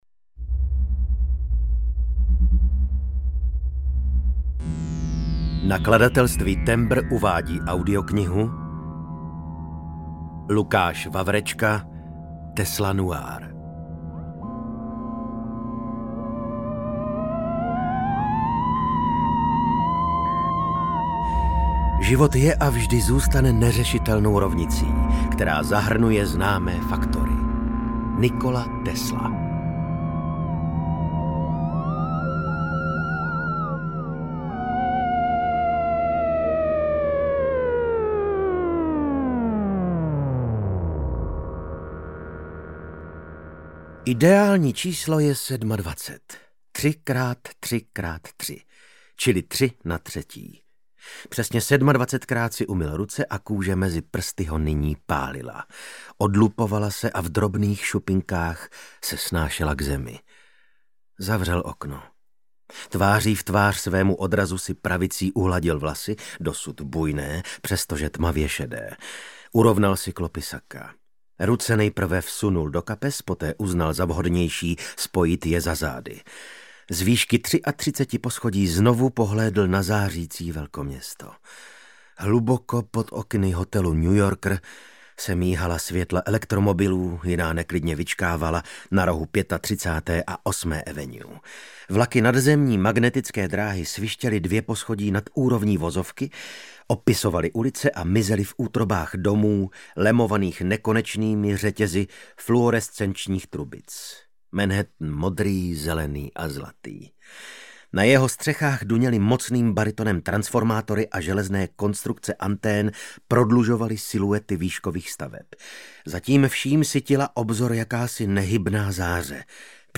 Tesla Noir audiokniha
Ukázka z knihy
• InterpretVasil Fridrich